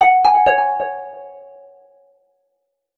new_event.ogg